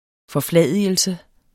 Udtale [ fʌˈflæˀˌðiˀəlsə ]